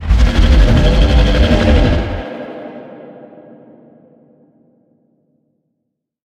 File:Sfx creature iceworm vo close 01.ogg - Subnautica Wiki
Sfx_creature_iceworm_vo_close_01.ogg